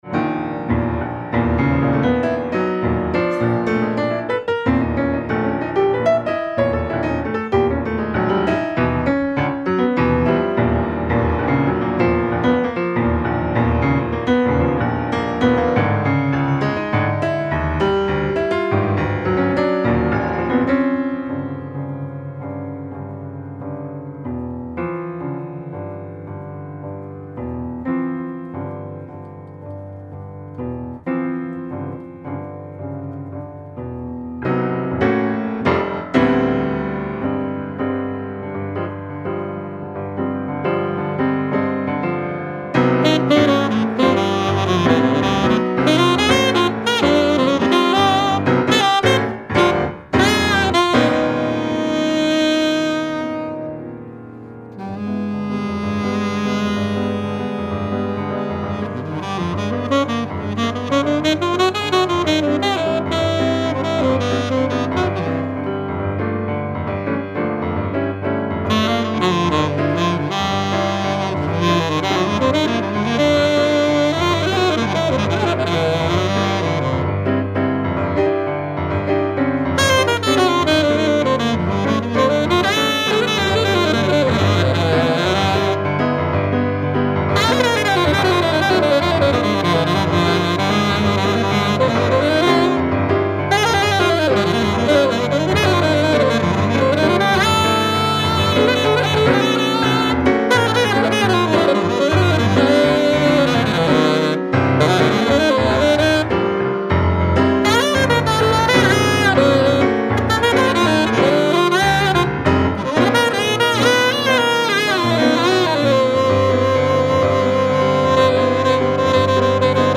(Tenor sax. Passage d'impro et thème final)